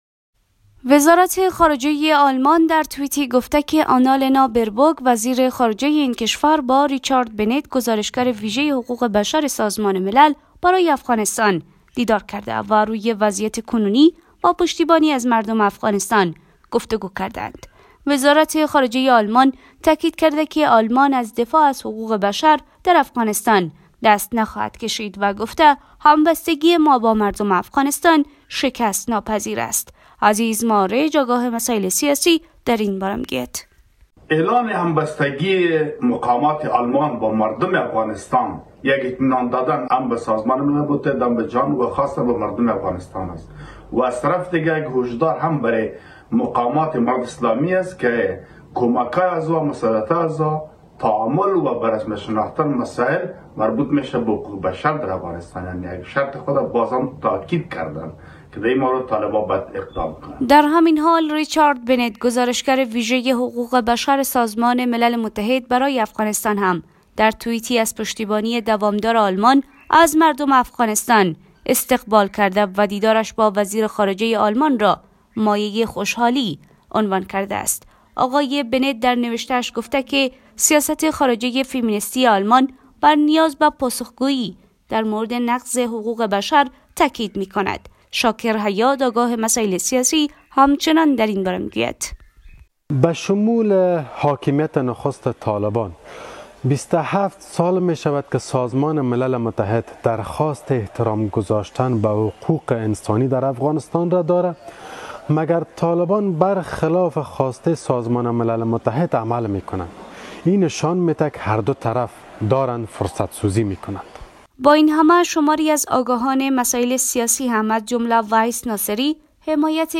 خبر رادیو